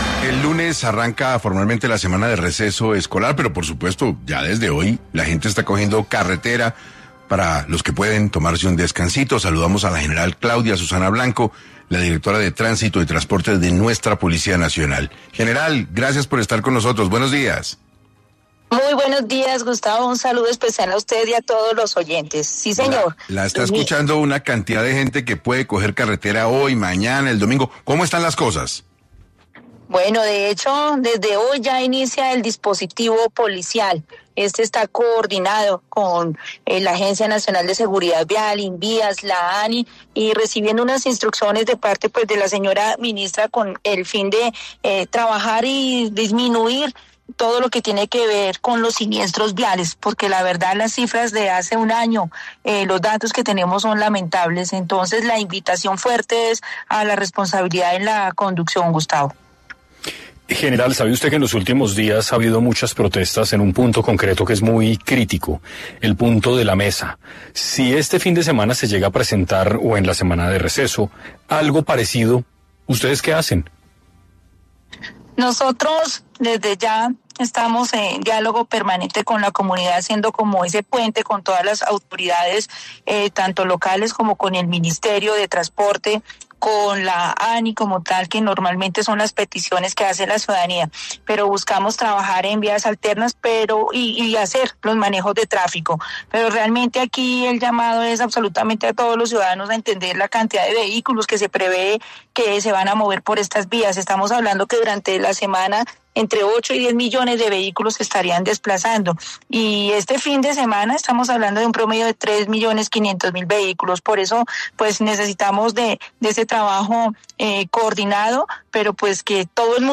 La general, Claudia Susana Blanco, directora de Tránsito y Transporte de la Policía Nacional pasó por 6AM, para hablar de las estrategias en las carreteras, durante la semana de receso escolar.